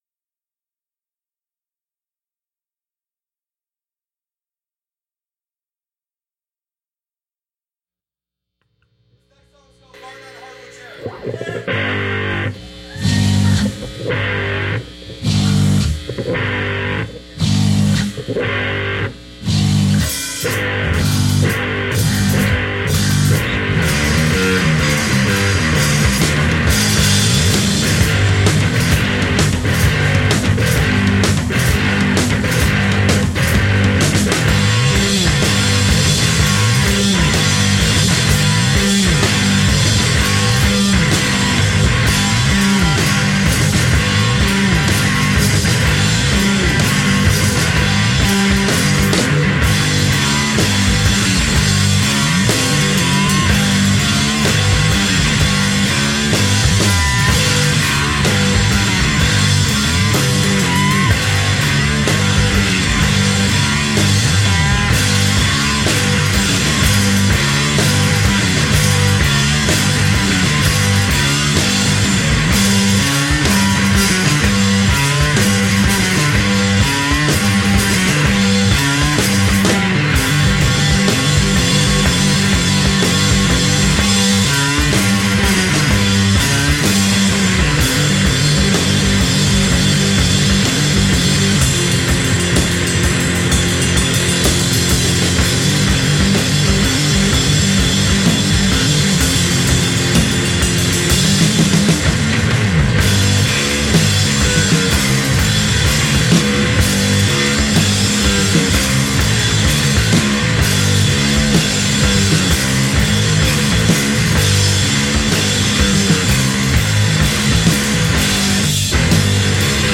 Recorded Live
in Ferndale, MI